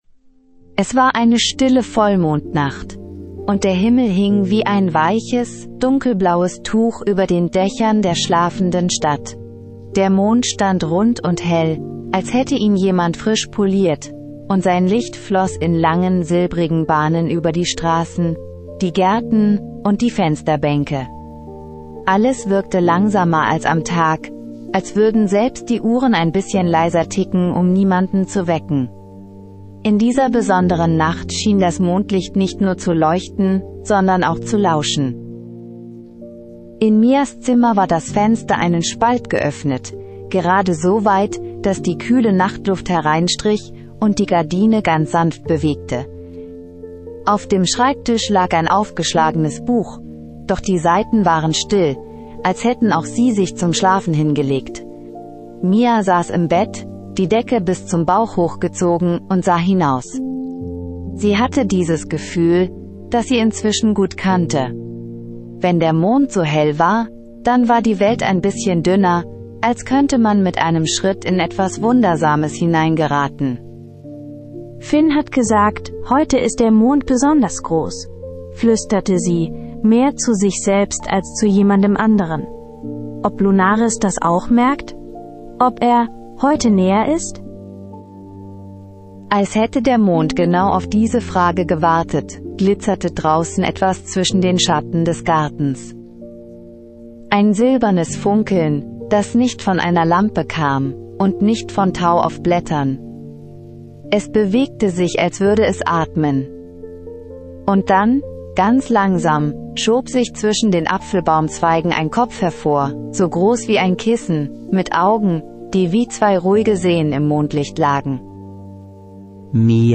Magisches Einschlaf-Hörspiel für Kinder